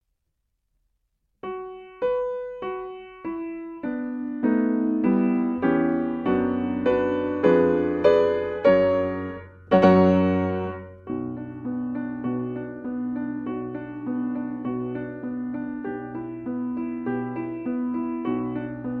Nagrania dokonane na pianinie Yamaha P2, strój 440Hz
Allegro moderato: 100 BMP